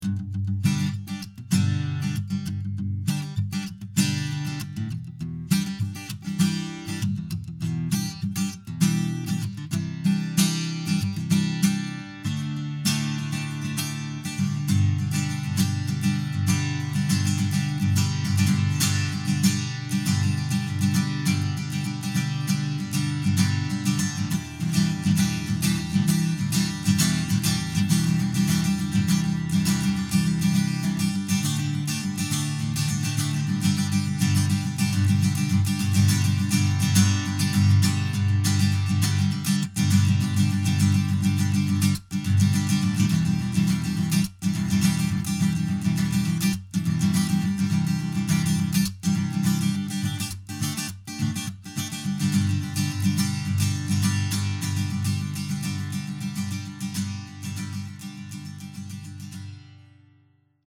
Accompagnamento acustico pag. 3.
La struttura base è composta da un chorus di 8 battute.
ascoltarne ora una versione ricca di spunti, più che altro per fare capire quante modi di accompagnare in strumming possono essere utilizzati all'interno di uno stesso brano.